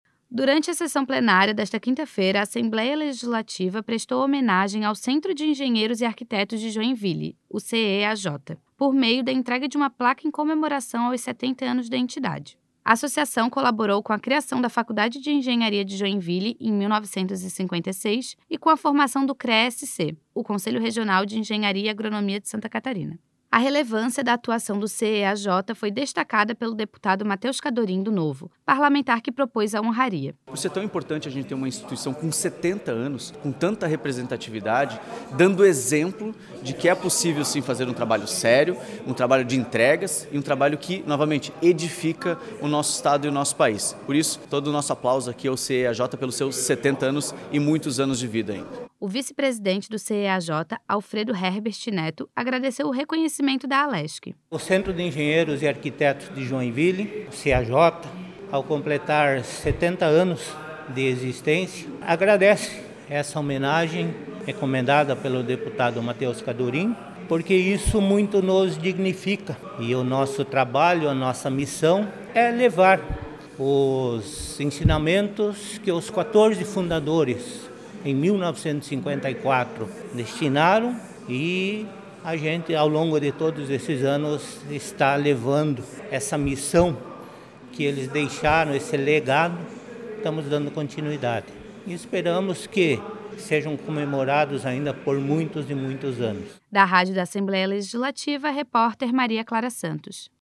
Durante a sessão plenária desta quinta-feira (13), a Assembleia Legislativa prestou homenagem ao Centro de Engenheiros e Arquitetos de Joinville (CEAJ) por meio da entrega de uma placa em comemoração aos 70 anos da entidade.
Entrevista com:
- deputado Matheus Cadorin (Novo);